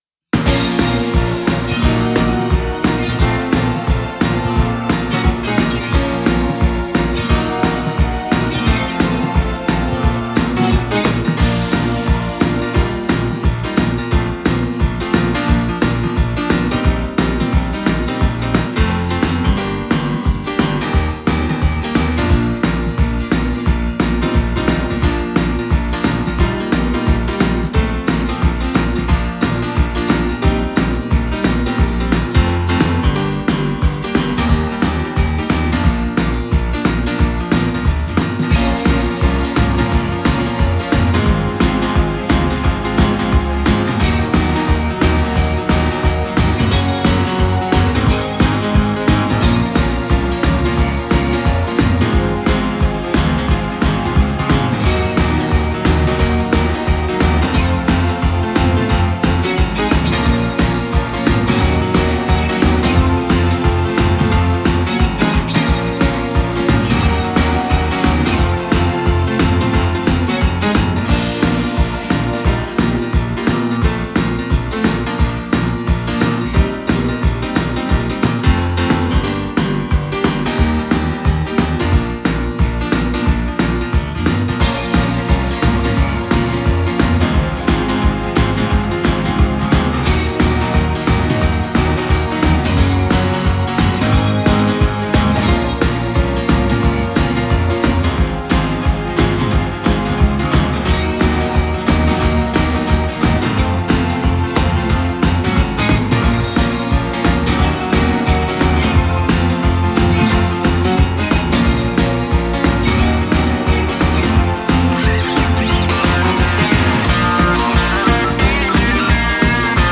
Genre: Pop.